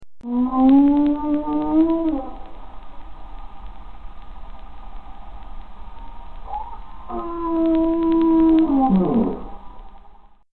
Humpback whale trumpet